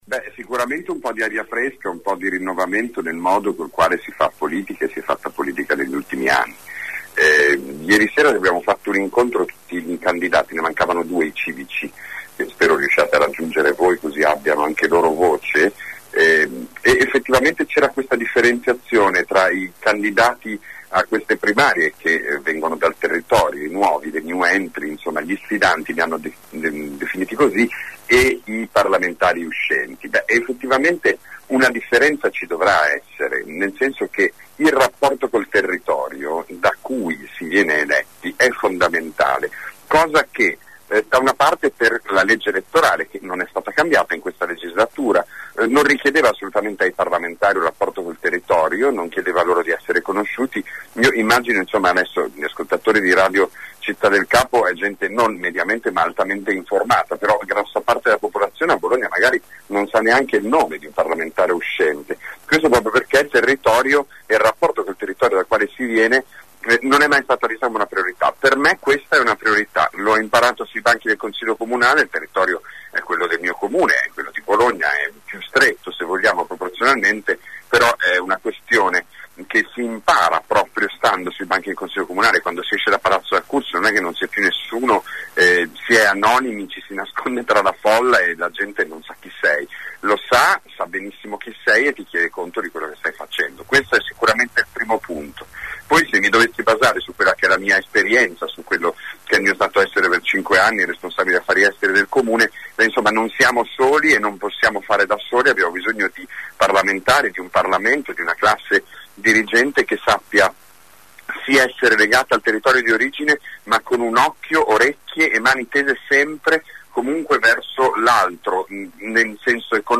Venerdì mattina durante AngoloB abbiamo organizzato una tavola rotonda con alcuni dei candidati.
Per il Pd abbiamo intervistato:
Benedetto Zacchiroli, consigliere comunale renziano